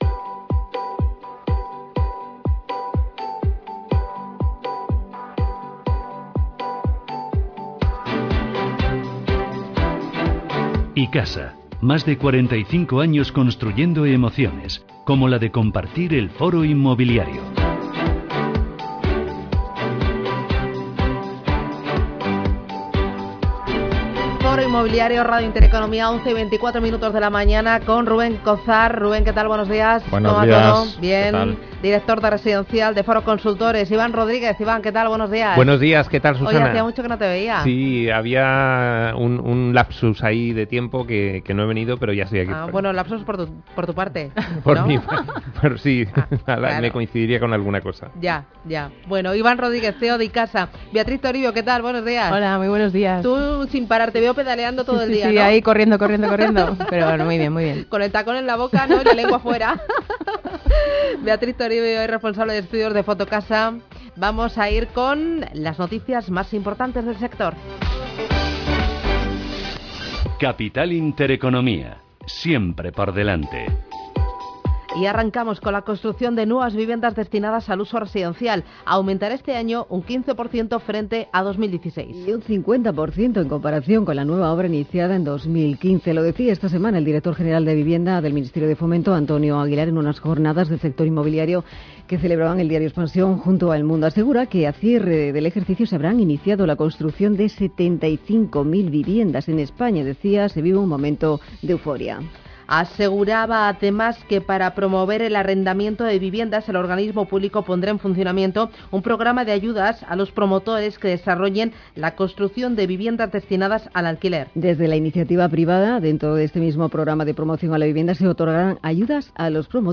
Estos temas, además de las actuaciones de los ayuntamientos, se han abordado en el programa Foro Inmobiliario de Intereconomía Radio. Diversos expertos del sector han expuesto sus soluciones a estos problemas, especialmente relevantes en la ciudad de Madrid.